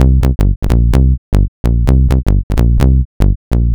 Jackin Bass C 128.wav